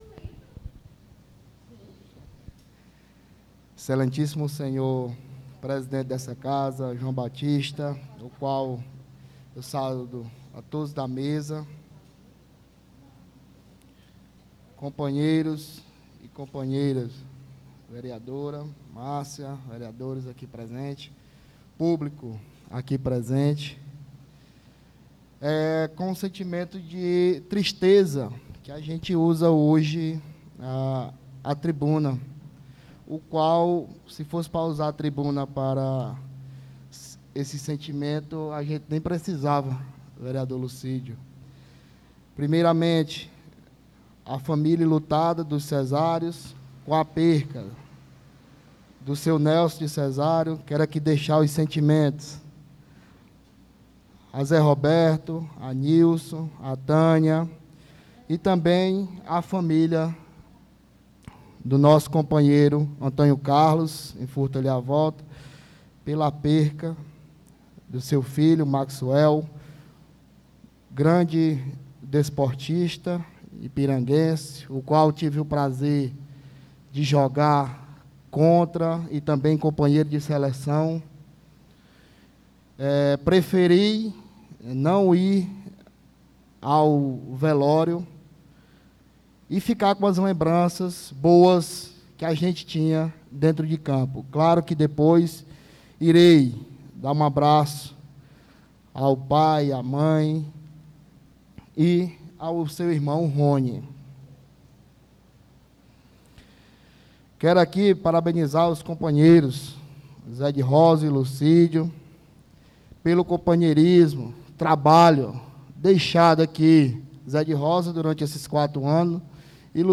Pronunciamento Ver Tiago Leal